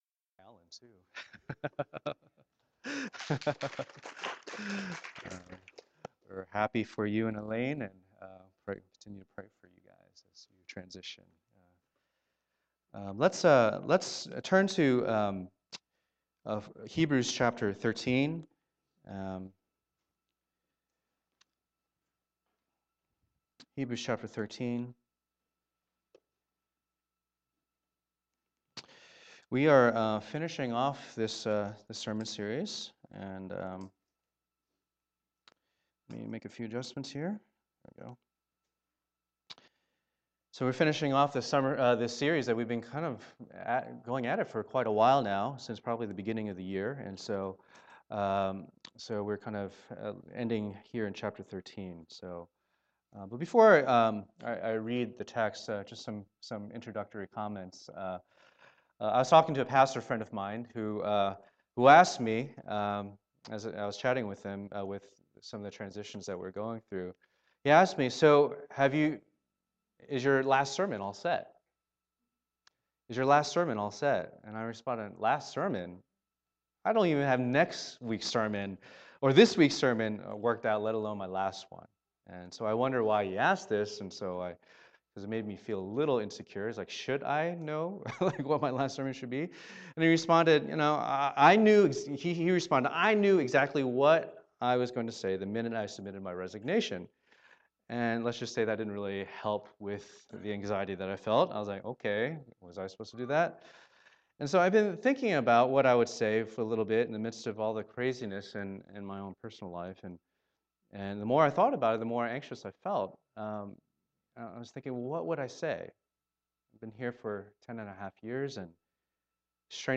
Passage: Hebrews 13:1-8 Service Type: Lord's Day